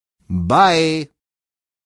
Звуки бай-бай